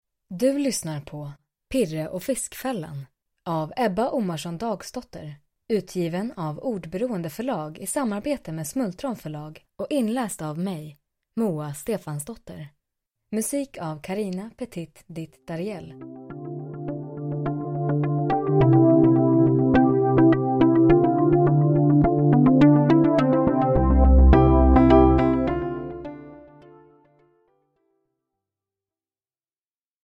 Pirre och fiskfällan – Ljudbok – Laddas ner